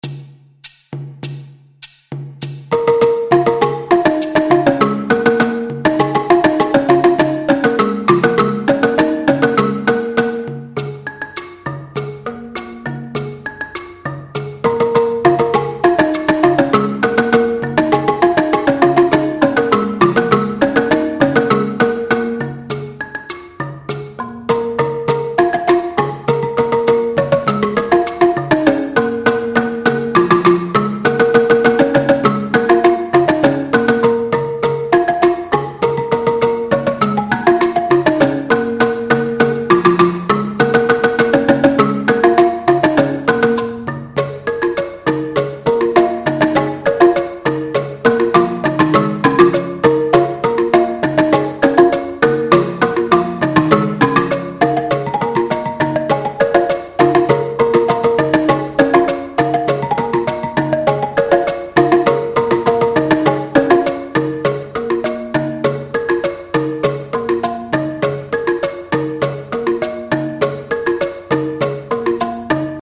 Pentatonische balafoon
201 bpm Arrangement
RitmeMuso-Bwe-201bpm.mp3